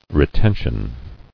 [re·ten·tion]